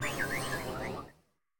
Cri de Terracruel dans Pokémon Écarlate et Violet.